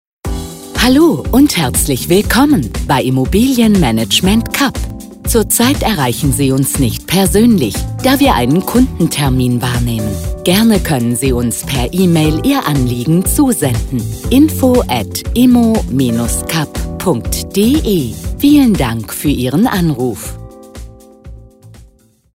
Telefonansagen mit echten Stimmen – keine KI !!!
Immobilien Management Kapp: AB Ansage